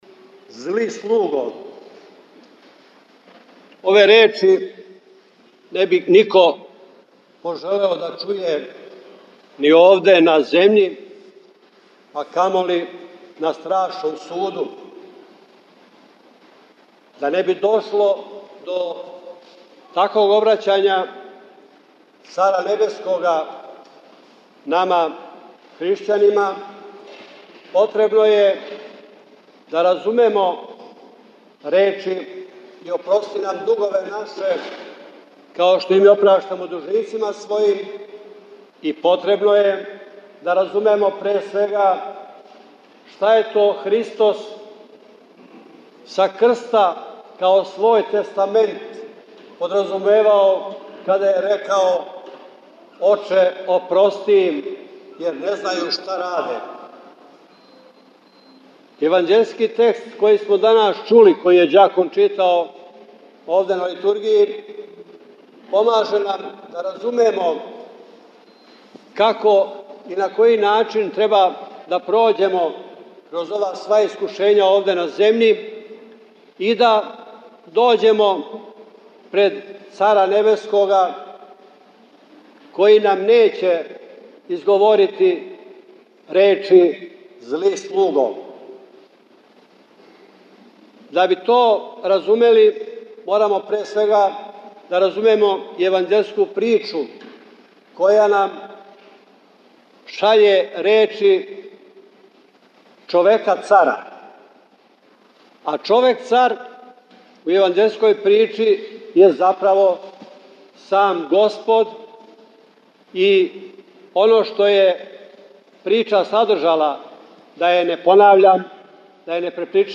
У малом храму св. Саве на Врачару у недељу 11. по Духовима, 23. августа 2020. године, братство је служило свету Литургију, уз молитвено учешће бројног верног народа.
Звучни запис беседе